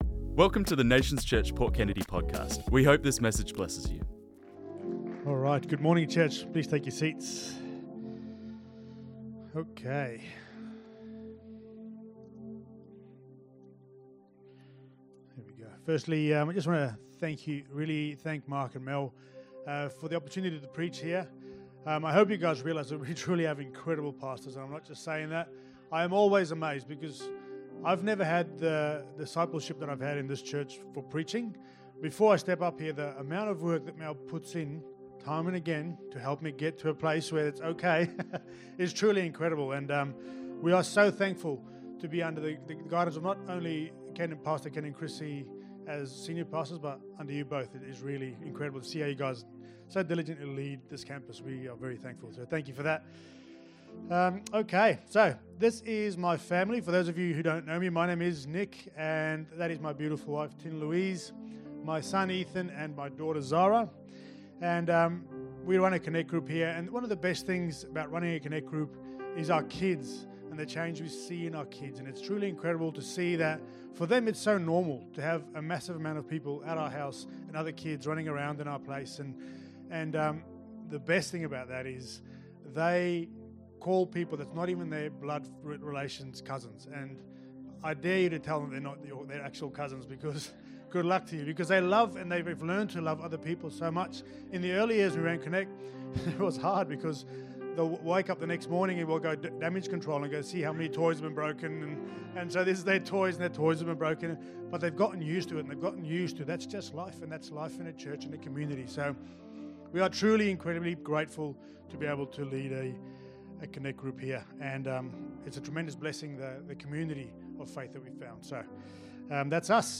This message was preached on Sunday 16th March 2025